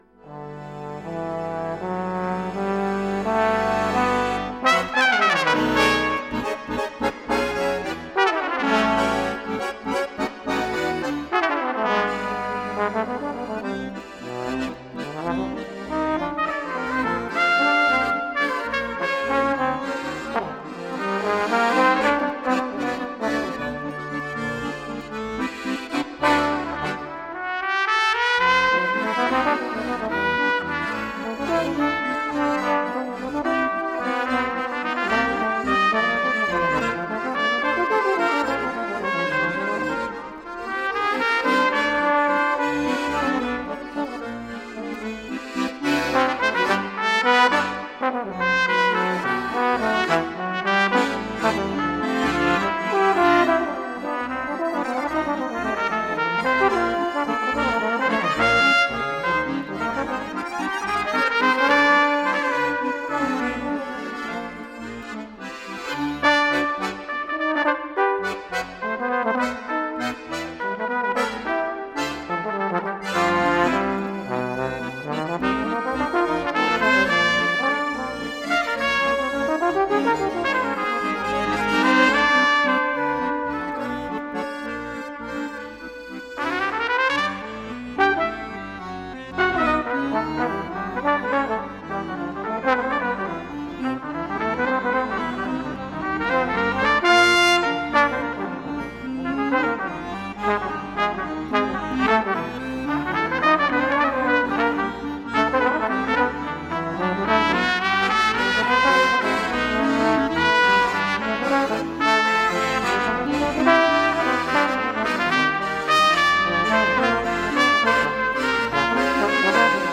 With bass trumpet.